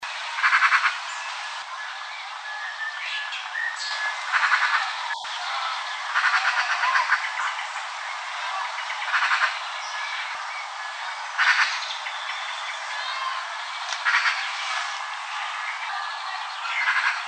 Eurasian Magpie (Pica pica)
713_0088-Magpie.MP3
Life Stage: Adult
Location or protected area: Milton Country Park
Condition: Wild
Certainty: Photographed, Recorded vocal